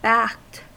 Ääntäminen
Ääntäminen US Tuntematon aksentti: IPA : /bækt/ Haettu sana löytyi näillä lähdekielillä: englanti Käännöksiä ei löytynyt valitulle kohdekielelle.